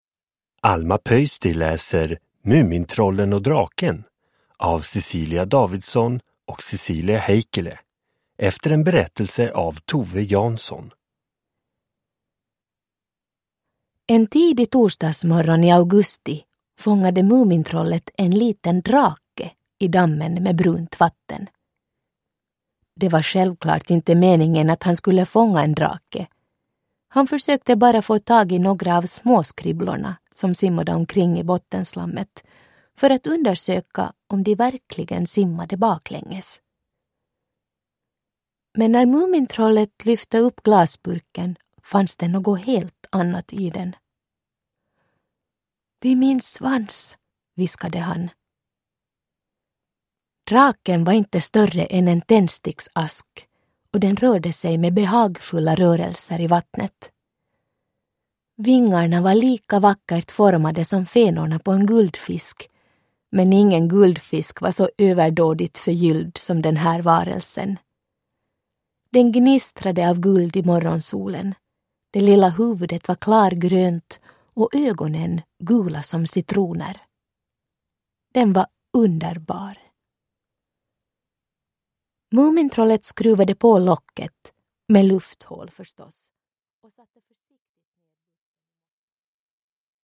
Mumintrollen och draken – Ljudbok – Laddas ner
Uppläsare: Alma Pöysti